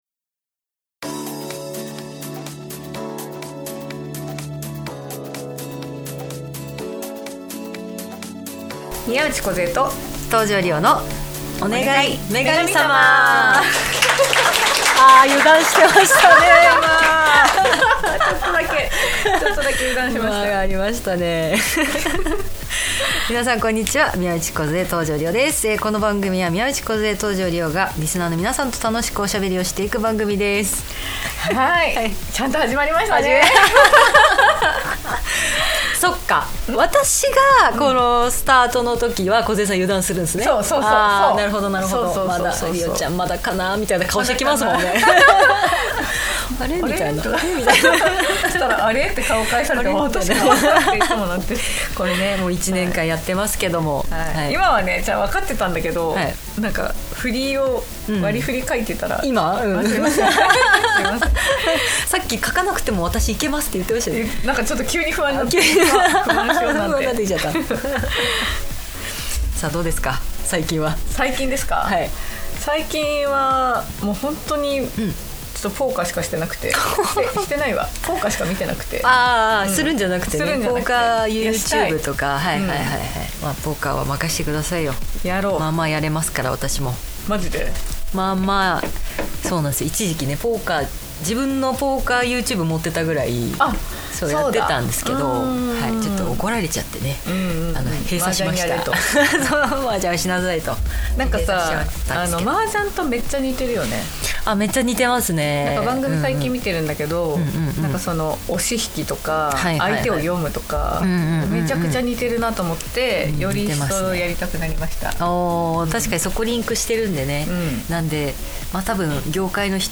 公開収録後半の部も沢山の方にご参加いただきました！お二人が美貌の秘訣をこっそり教えてくれました♪